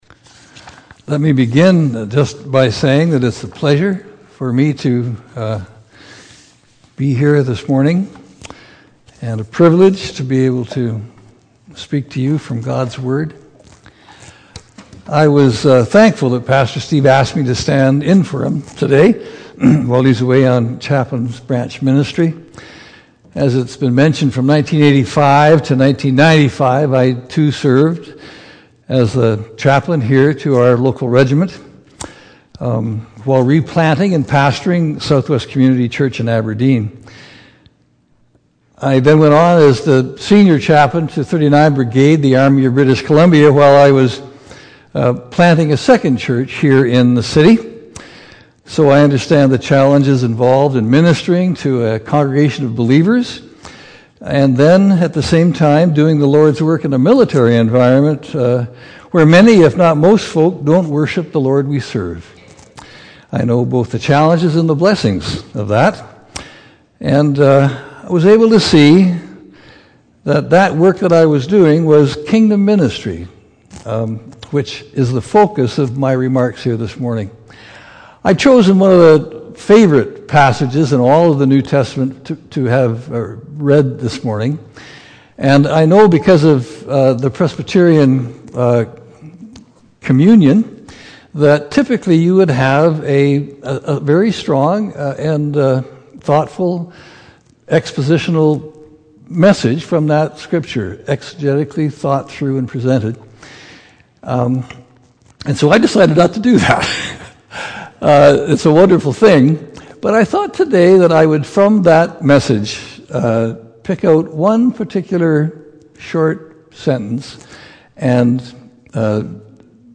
Services from St. Andrew's | St. Andrew’s Presbyterian Church